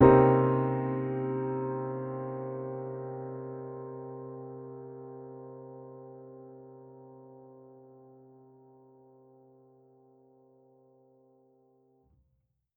Index of /musicradar/jazz-keys-samples/Chord Hits/Acoustic Piano 1
JK_AcPiano1_Chord-Cm7b9.wav